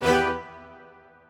admin-leaf-alice-in-misanthrope/strings34_1_019.ogg at a8990f1ad740036f9d250f3aceaad8c816b20b54